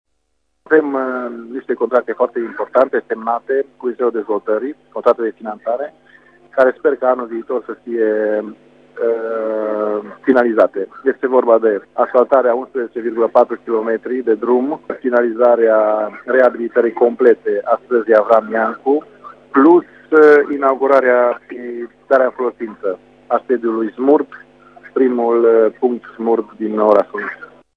Cele mai importante proiecte pe 2015 ale oraşului Luduş sunt asfaltarea a 11,5 km de drumuri şi darea în folosinţă a unui sediu SMURD, afirma primarul Cristian Moldovan.